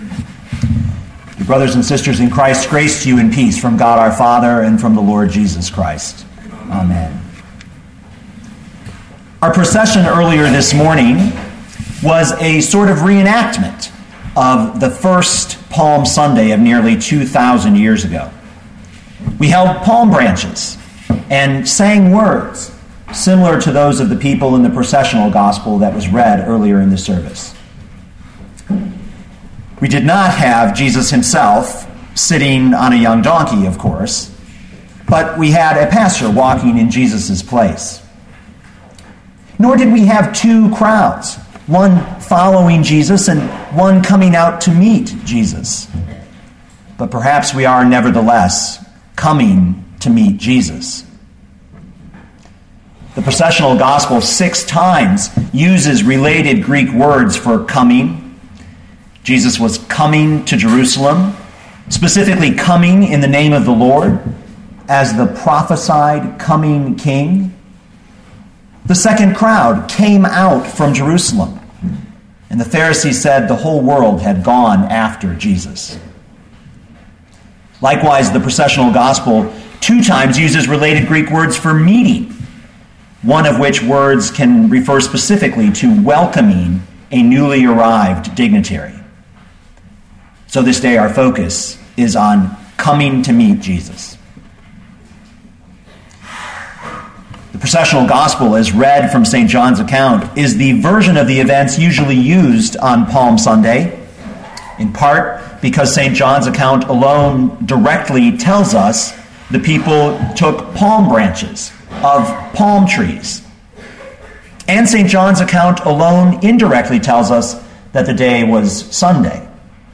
2013 John 12:12-19 Listen to the sermon with the player below, or, download the audio.